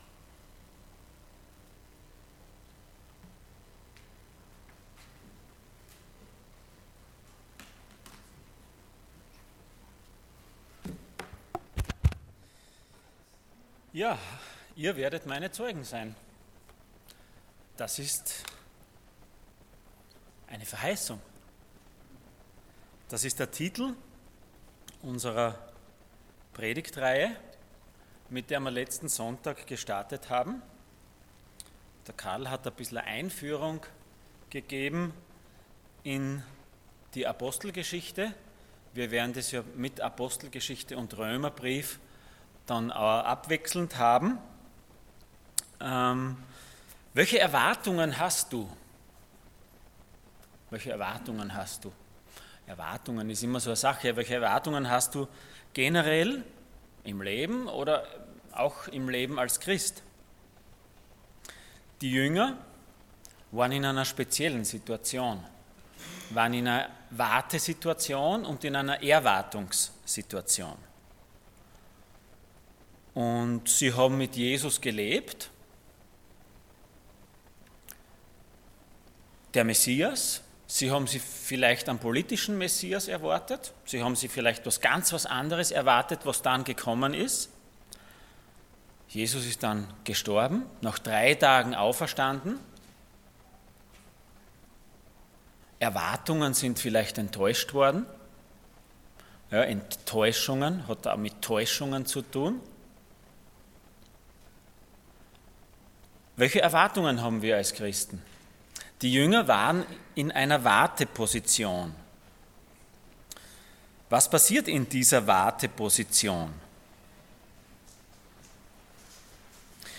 Passage: Acts 1:6-26 Dienstart: Sonntag Morgen